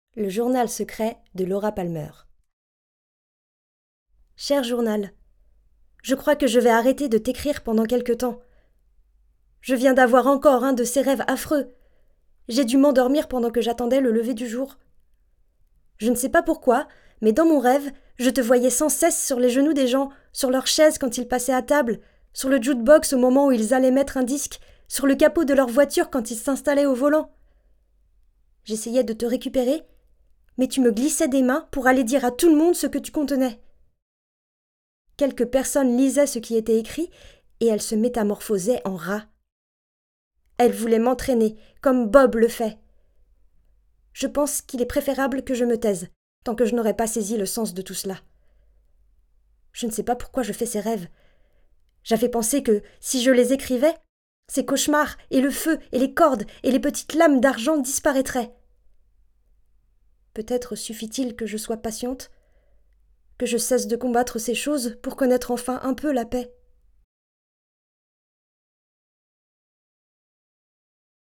Narration Laura Palmer - Jennifer Lynch
14 - 34 ans - Soprano